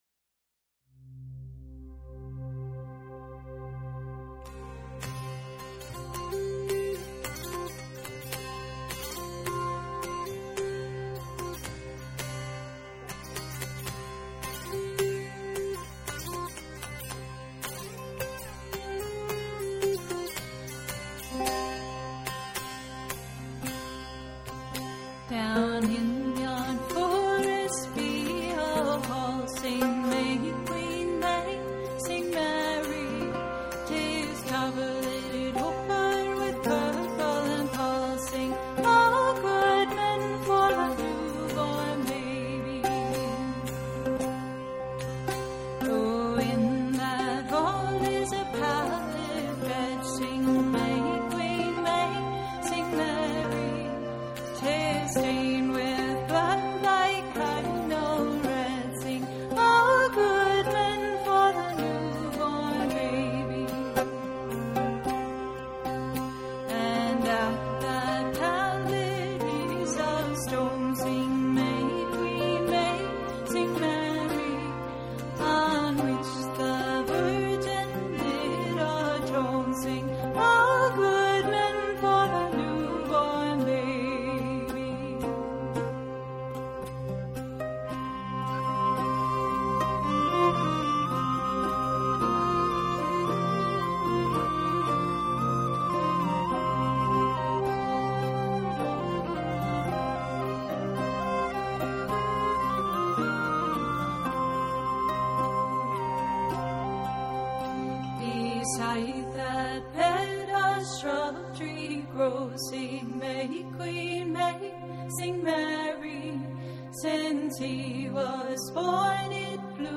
Summary This is our annual holiday special, featuring music, poetry, stories, and recipes!